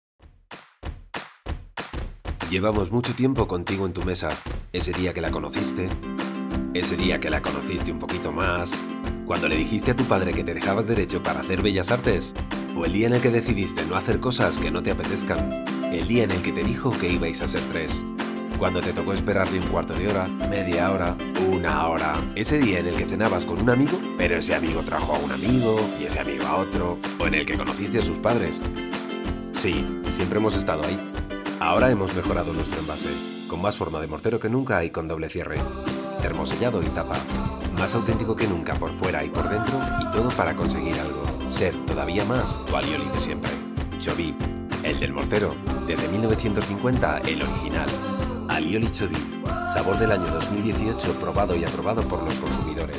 Spanish voice over artist. Will do any voice over in neutral Spanish
kastilisch
Sprechprobe: Werbung (Muttersprache):
Clear middle aged voice for any audiovisual project.